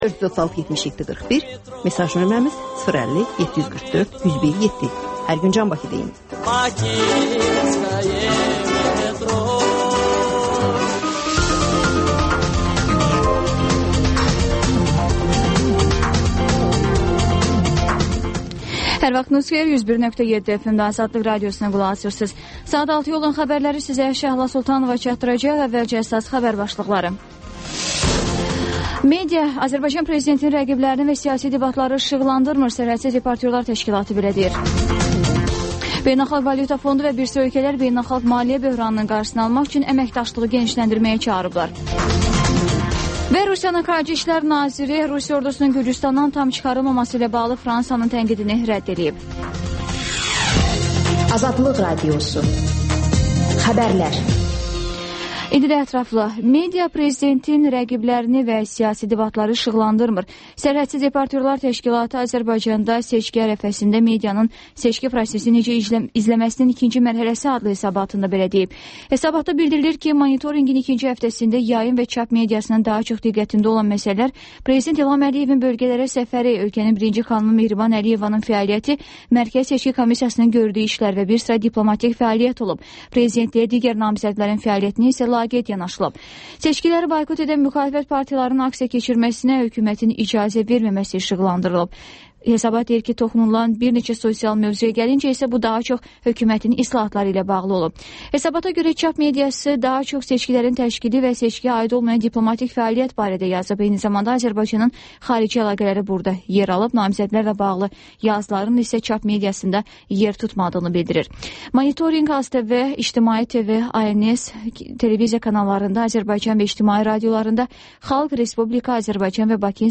Xəbərlər, QAYNAR XƏTT: Dinləyici şikayətləri əsasında hazırlanmış veriliş, sonda 14-24